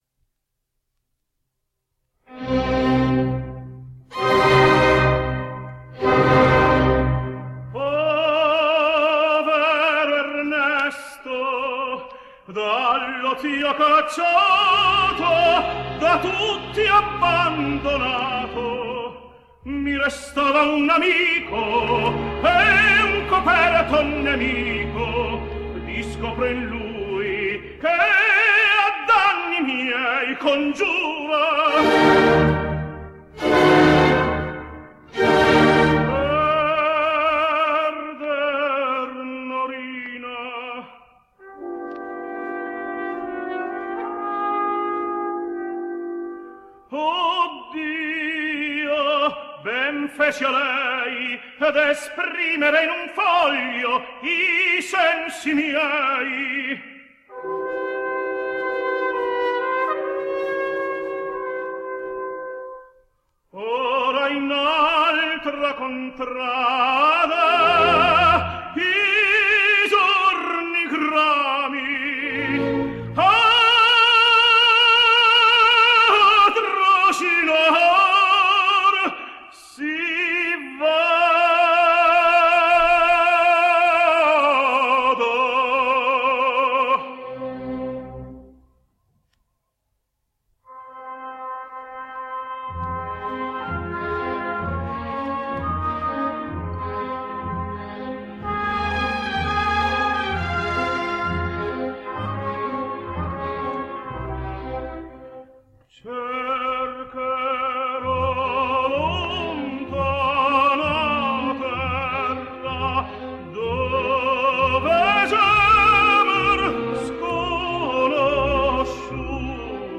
Italian Tenor.
From the Italian school, I’ve selected an Aria from the so-called belcanto school, Donizetti’s Don Pasquale..